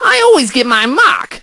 crow_kills_03.ogg